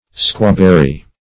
Search Result for " squawberry" : The Collaborative International Dictionary of English v.0.48: Squawberry \Squaw"ber`ry\ (-b[e^]r`r[y^]), n. (Bot.) A local name for the partridge berry; also, for the deerberry.